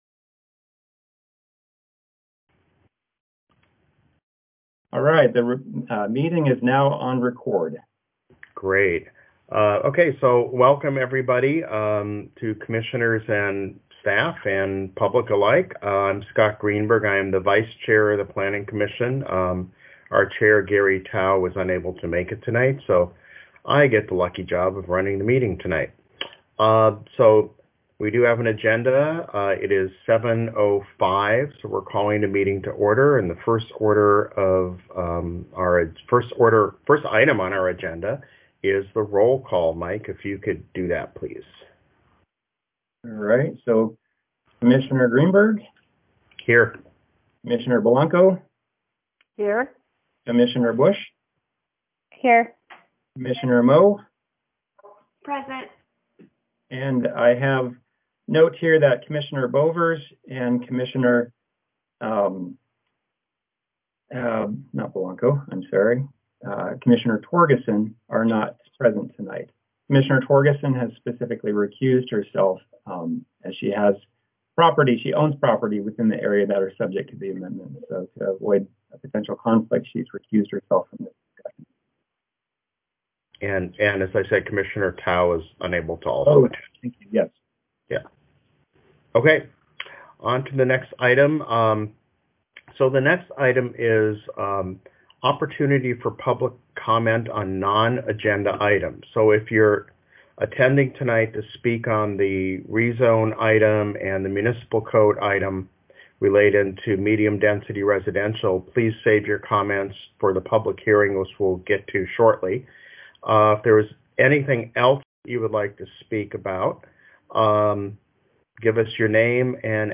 Planning Commission Audio - November 12, 2020